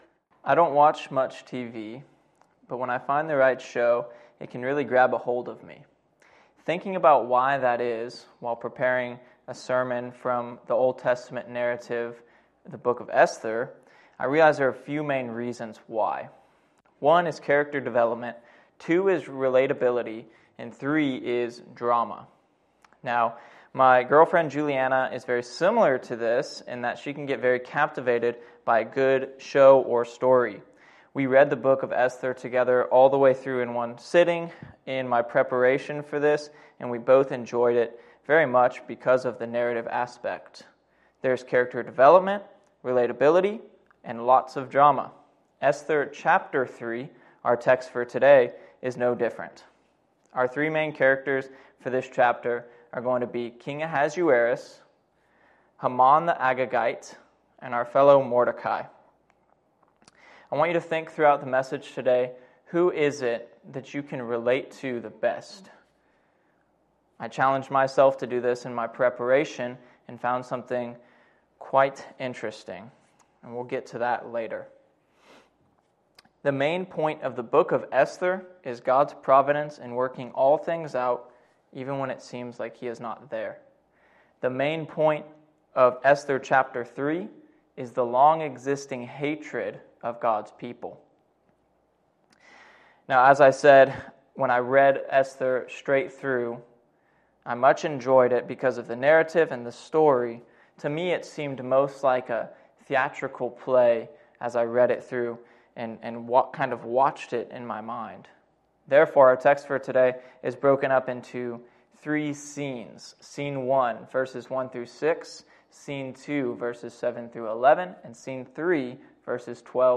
“A Biblical Drama” Preacher